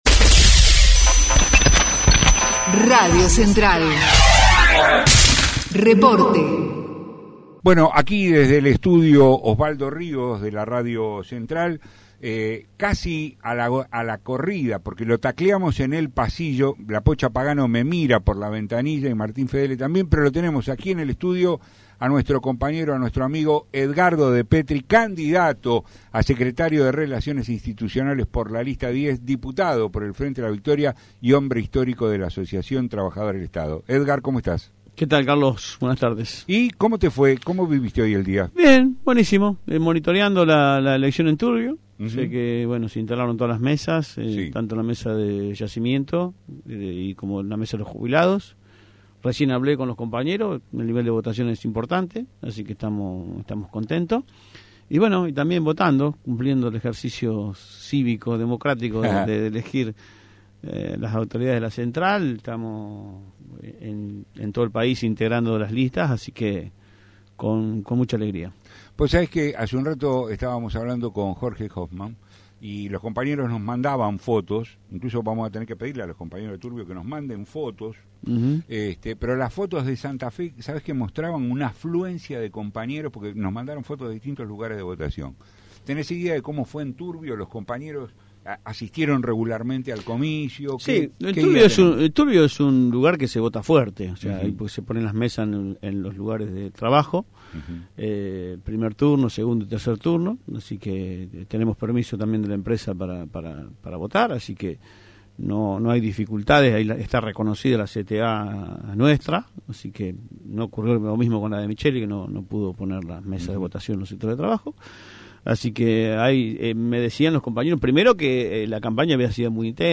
EDGARDO DEPETRI (entrevista) RADIO CENTRAL – Central de Trabajadores y Trabajadoras de la Argentina